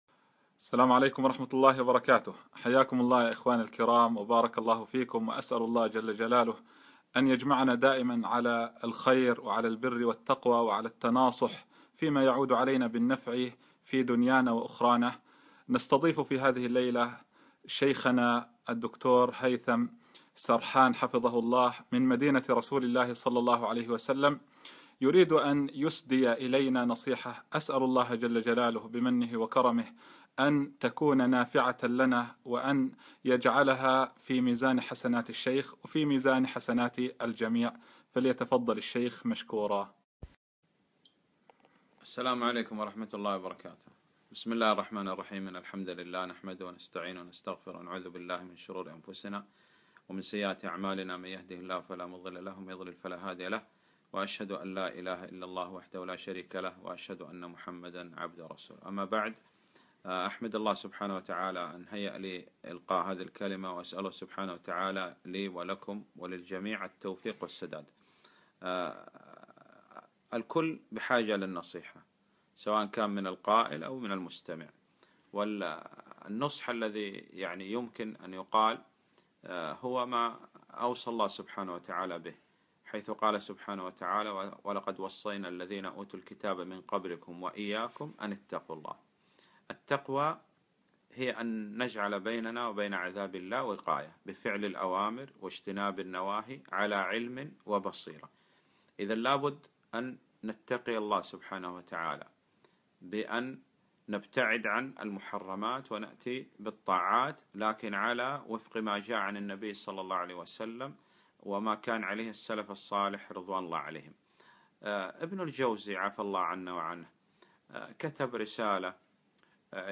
نصيحة مقدمة من الشيخ